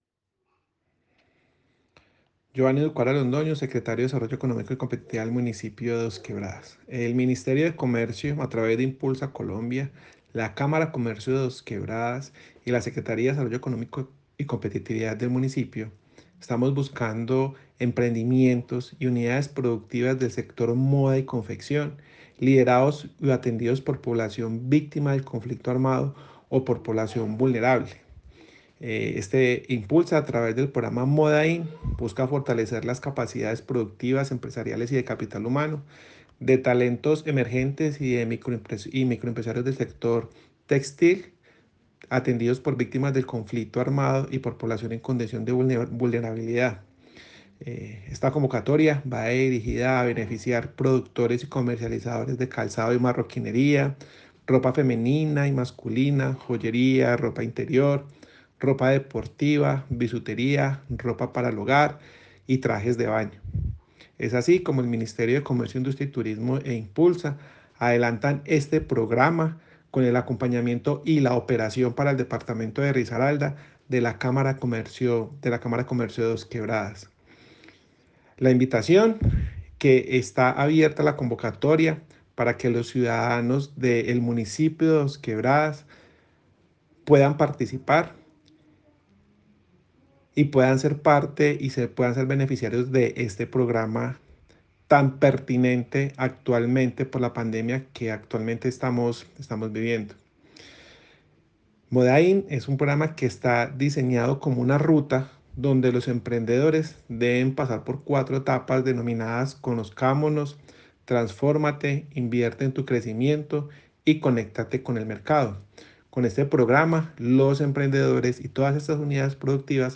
Comunicado-192-Audio-1-Secretario-Desarrollo-Economico-y-Competitividad-Geovanny-Ducuara-Londono.mp3